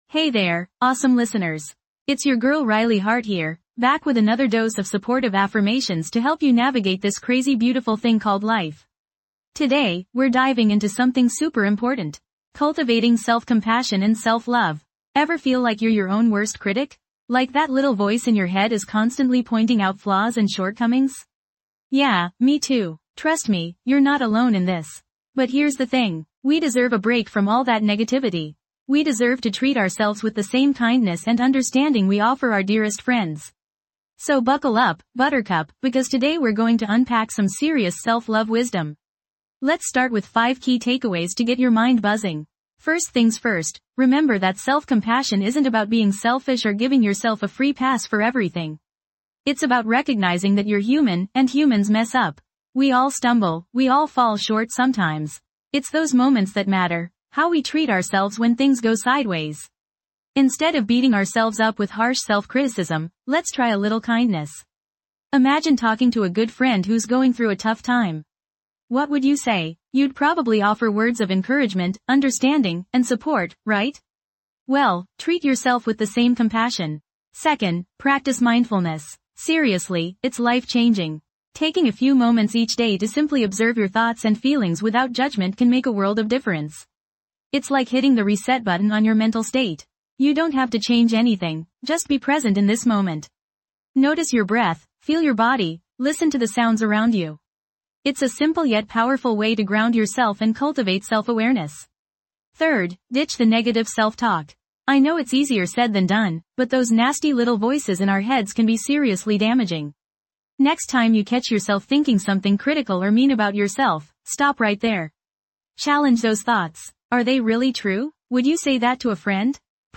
This podcast provides a safe space for gentle, anxiety-reducing affirmations designed to soothe your mind, challenge unhelpful thoughts, and empower you with self-compassion. Each episode offers a curated selection of affirming statements delivered in a calming voice, guiding you towards a calmer, more confident state of being.